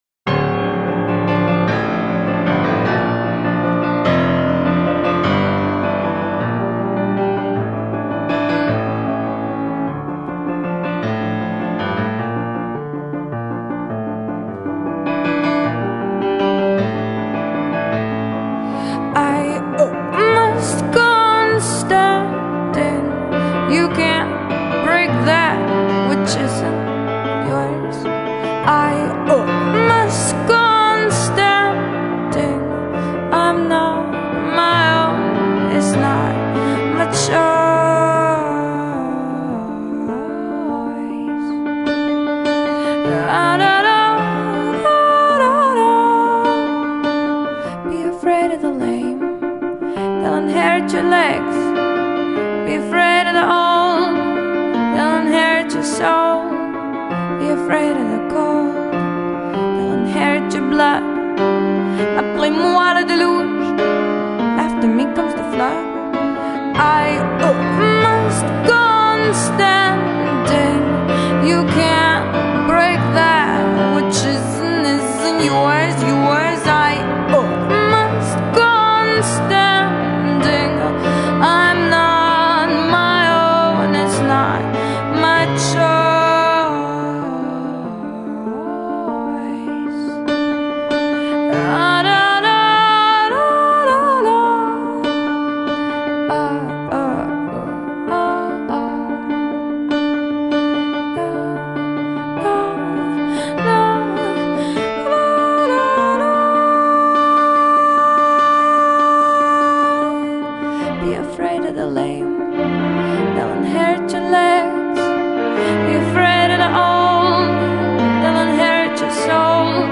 Posted in Indie Rock, piano on March 25th, 2007 5 Comments »
Her classical training really burns through this song.